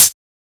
Closed Hats
edm-hihat-11.wav